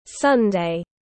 Chủ nhật tiếng anh gọi là sunday, phiên âm tiếng anh đọc là /ˈsʌn.deɪ/
Sunday /ˈsʌn.deɪ/